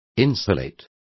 Complete with pronunciation of the translation of insulates.